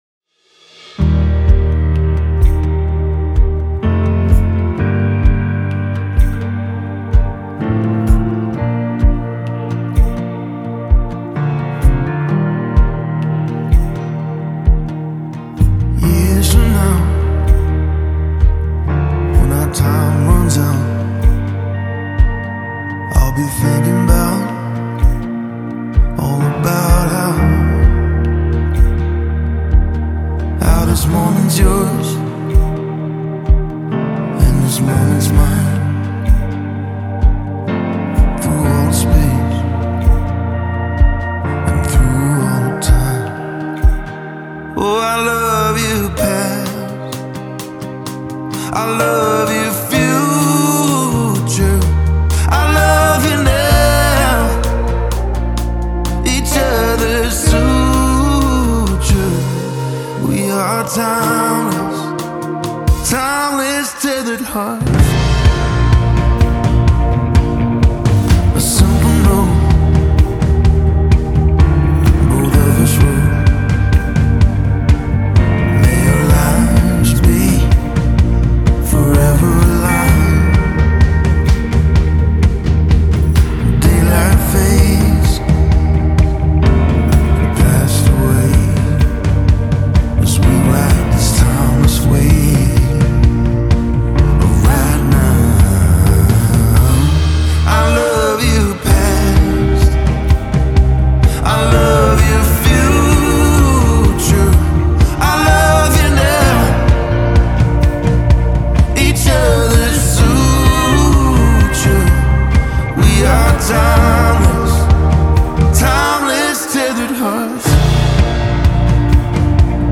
Part crooner, part troubadour, all storyteller.